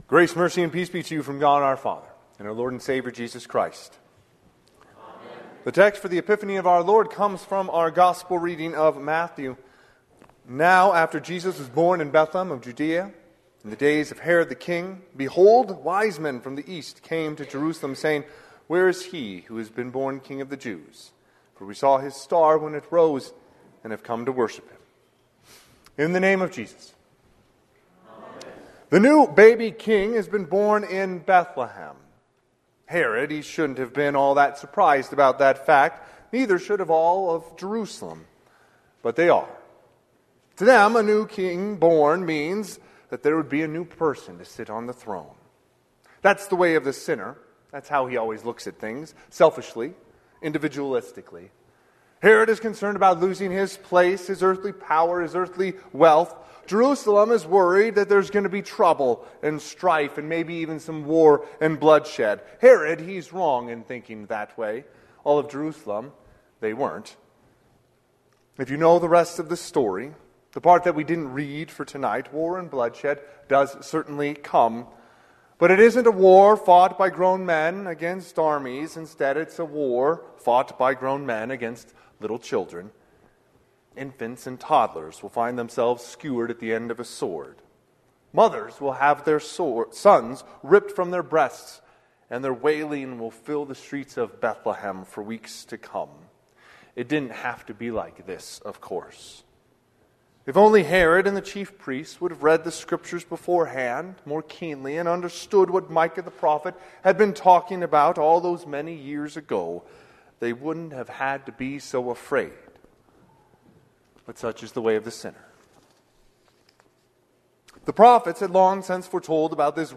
Sermon - 1/6/2025 - Wheat Ridge Lutheran Church, Wheat Ridge, Colorado
Sermon_Epiphany_Jan6_2025.mp3